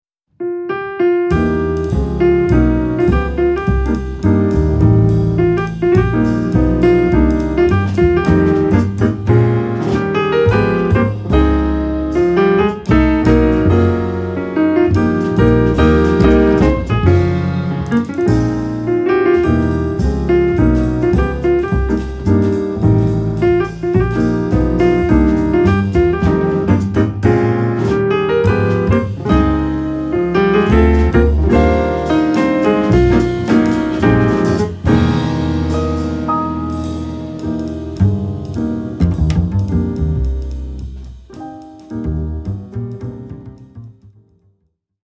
Piano
Bass
Drums
新しい和音の響きをお楽しみください。